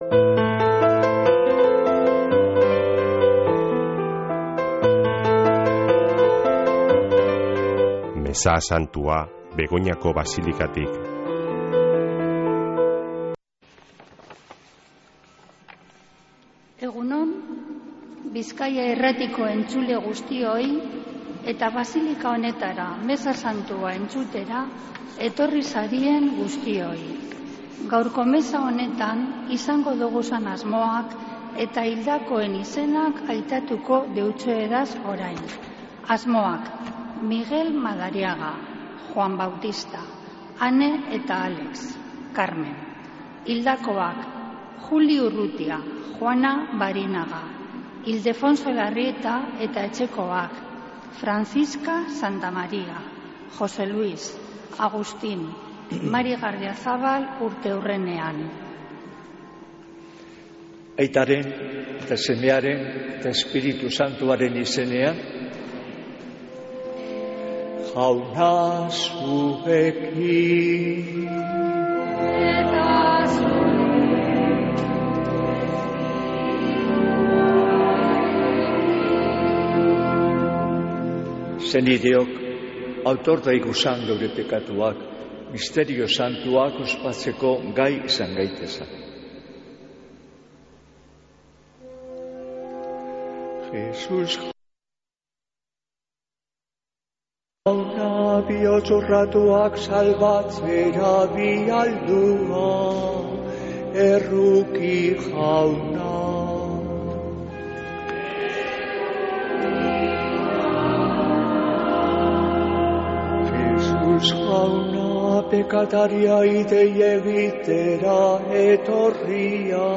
Mezea Begoñako basilikatik | Bizkaia Irratia
Mezea (25-04-28)